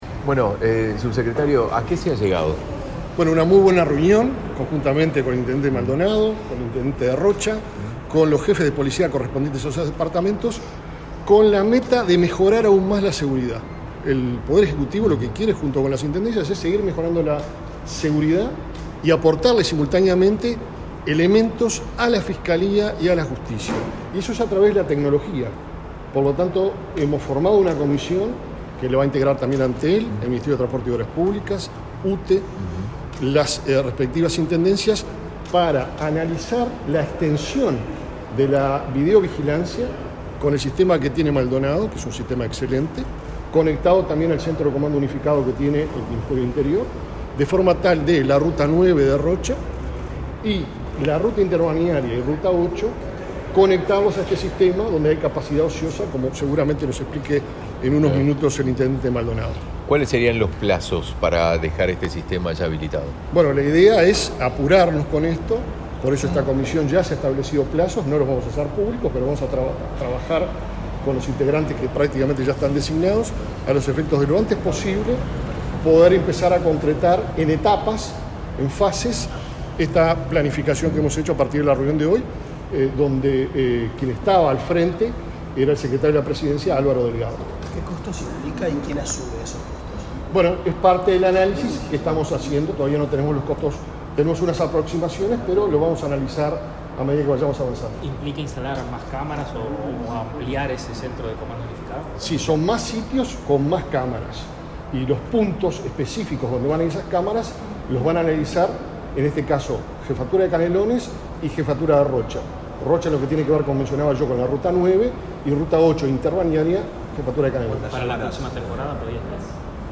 Declaraciones a la prensa del subsecretario del Ministerio del Interior, Guillermo Maciel
El Gobierno nacional firmó, este 20 de julio, un convenio con las intendencias de Rocha y Canelones para la expansión del Centro de Videovigilancia de Maldonado a esos departamentos, tras la reunión del secretario de la Presidencia, Álvaro Delgado, con autoridades del Ministerio del Interior e intendentes. El subsecretario Guillermo Maciel efectuó declaraciones la prensa.